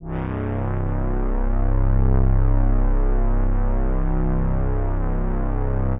C1_trance_pad_1.wav